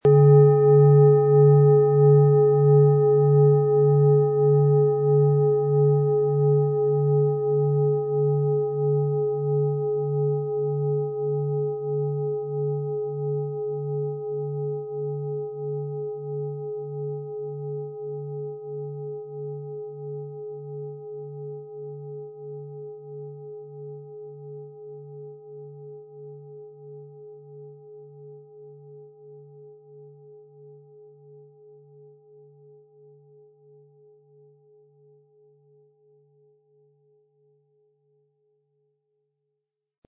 Handgetriebene, tibetanische Schale mit dem Planetenton Uranus.
• Tiefster Ton: Mars
Die Frage wie diese angebotene Schale klingt, beantwortet unser Klangbeispiel.
MaterialBronze